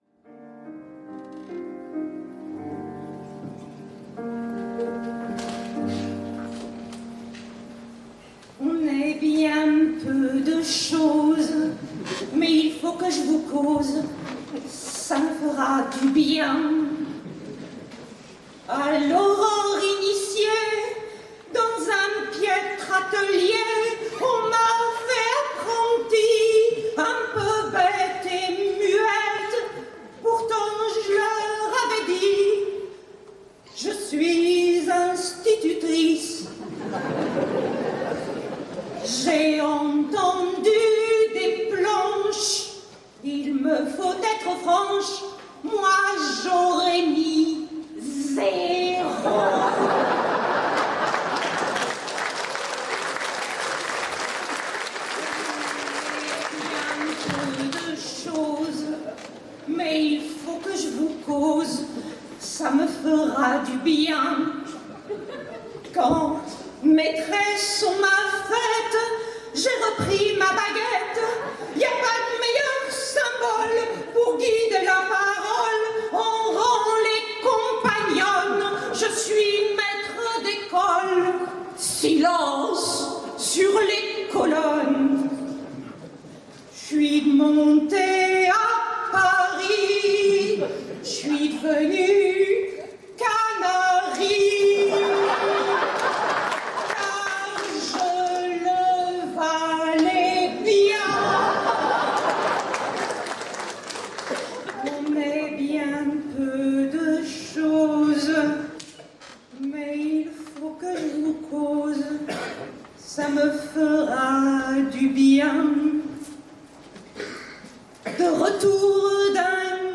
Chansons maçonneuses                                Suivante
Enregistrement public